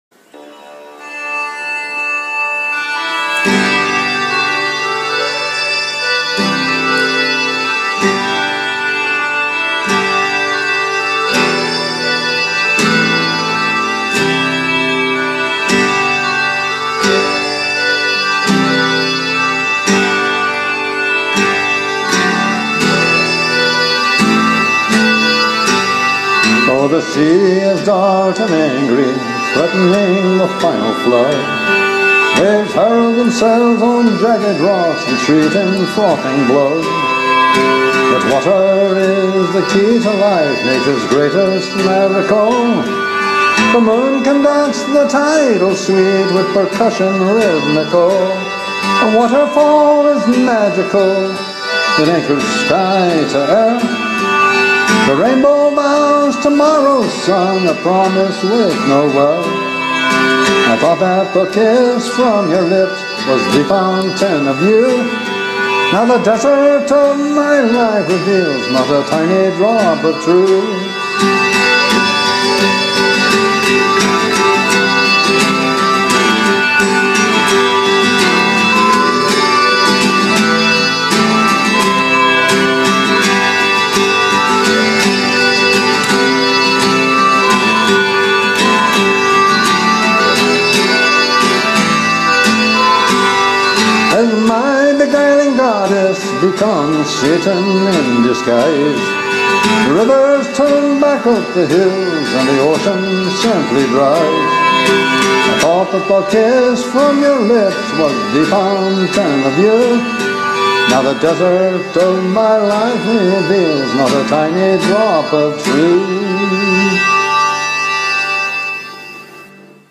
this poem put to music